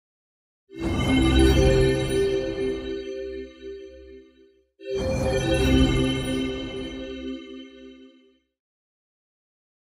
Звуки феи
Появление и исчезновение феи